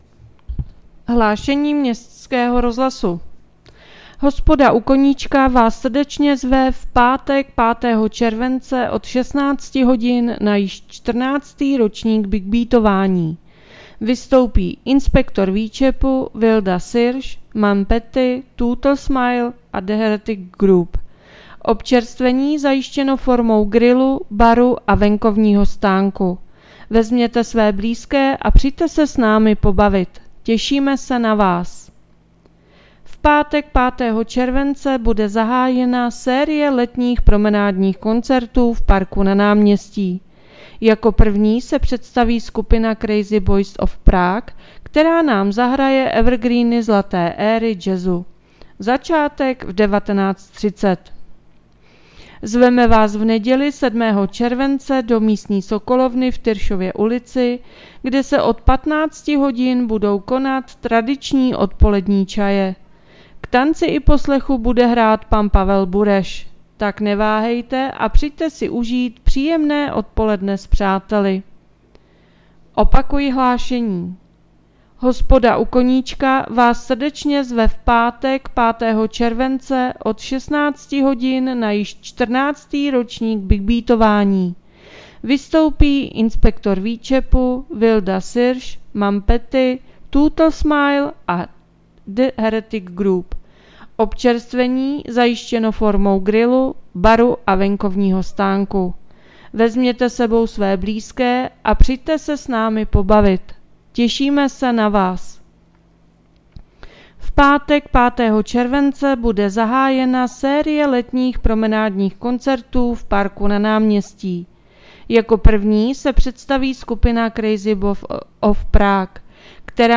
Hlášení městského rozhlasu 4.7.2024